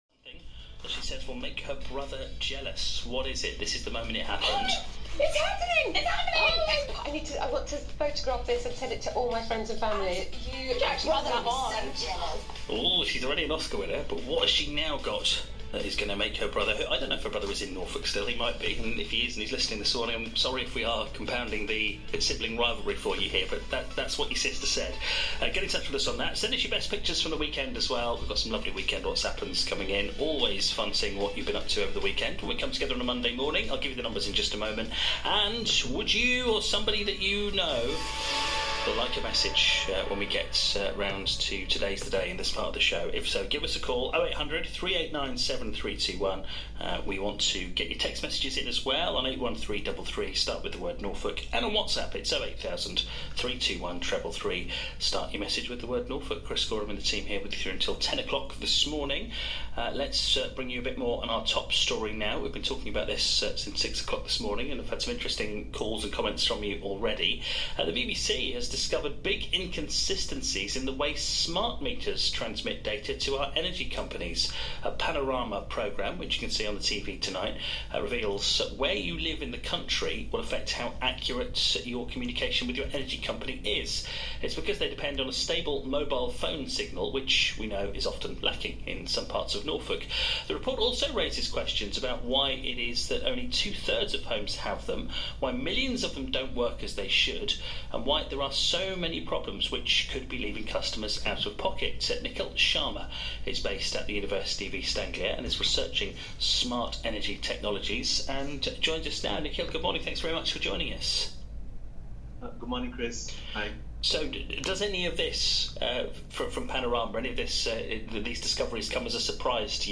appeared on BBC Norfolk Radio to talk about his research on smart meters in Great Britain. On 11 November 2024, he participated in an interview with BBC Norfolk Radio, where they explored the rollout of smart meters in Great Britain. Despite being one of the most expensive government-initiated programs in British history, these internet-enabled energy meters have faced criticism for failing to meet targets and not performing as expected.